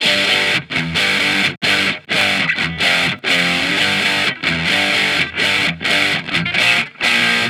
Guitar Licks 130BPM (14).wav